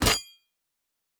Weapon UI 02.wav